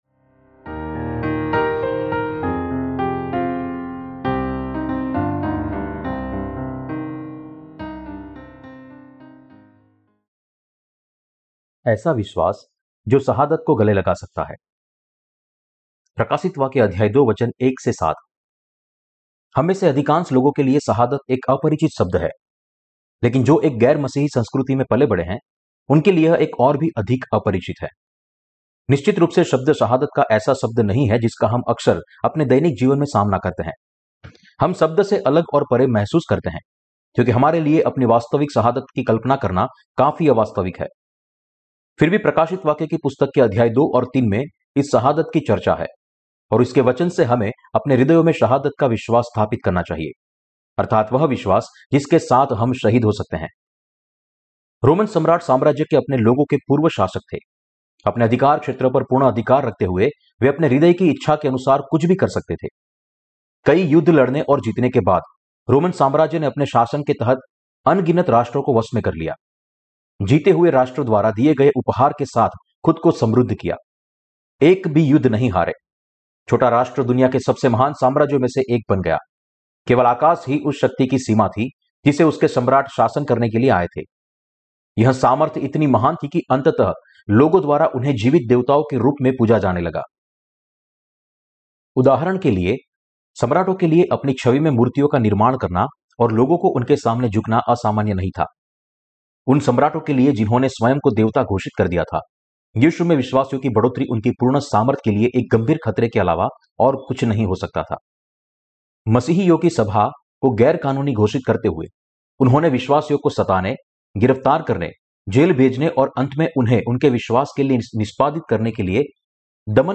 प्रकाशितवाक्य की किताब पर टिप्पणी और उपदेश - क्या मसीह विरोधी, शहादत, रेप्चर और हजार साल के राज्य का समय नज़दीक है?